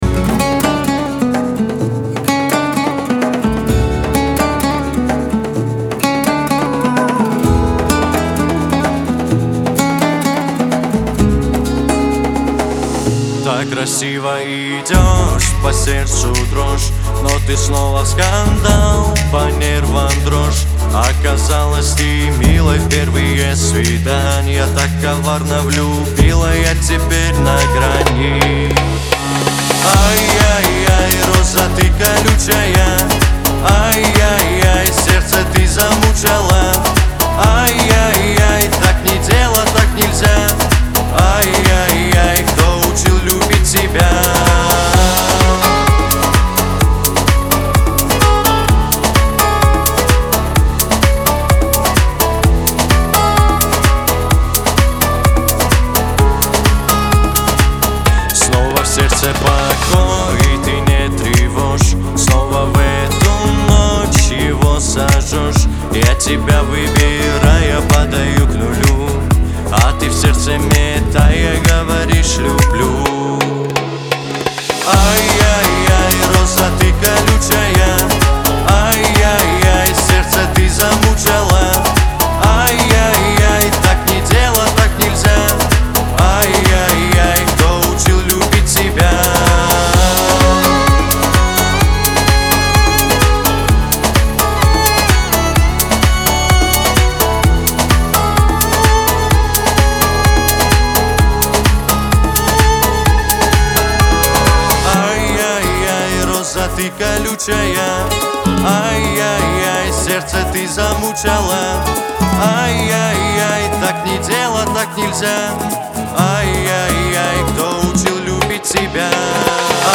грусть
Кавказ поп
Лирика